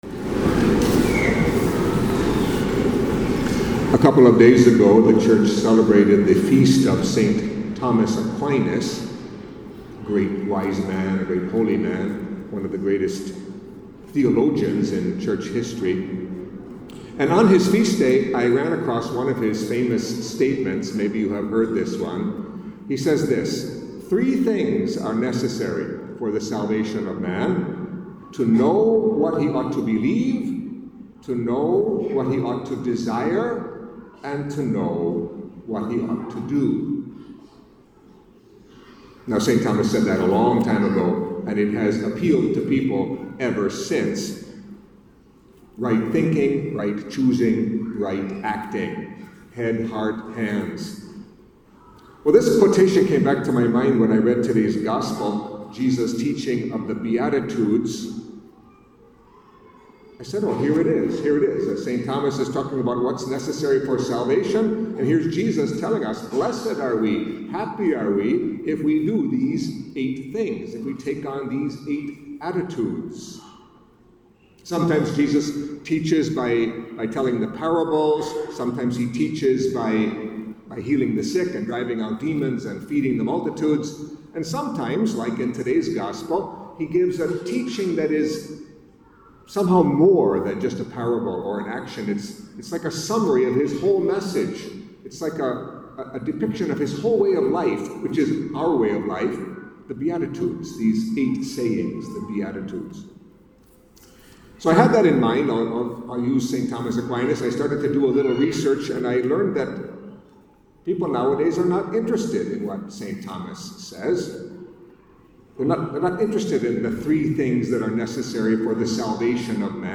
Catholic Mass homily for Fourth Sunday in Ordinary Time